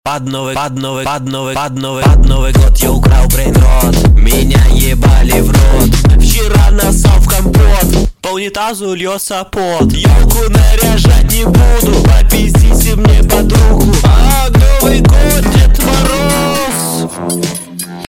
pod novyi god nariot moroz Meme Sound Effect